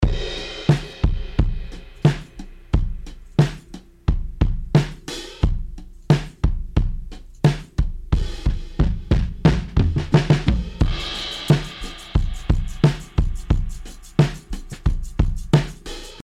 Note: In these examples, Version A is with no effect, Version B is with a bit of top end added using the HF Driver, and Version C is a saturated, vintage, noisy special effect sound.
Drums-Studer-Special-Effect-Norm_01-01.mp3